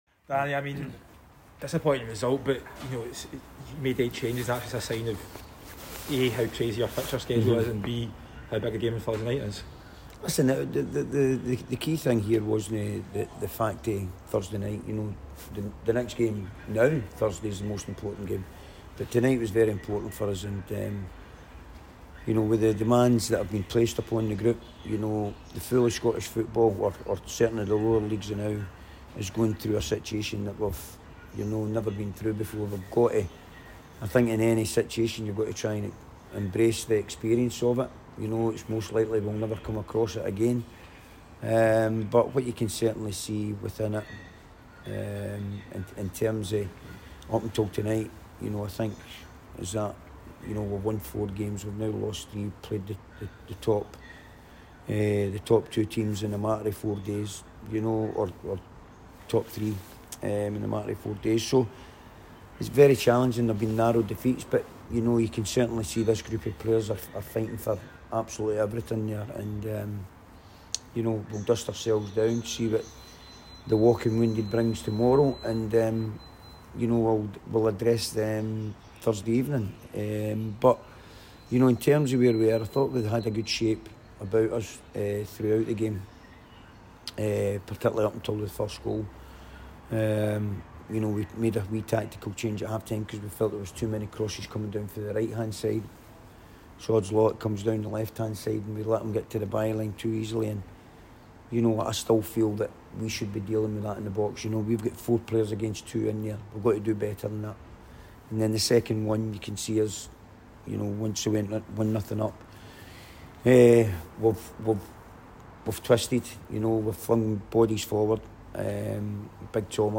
press conference after the League 1 match.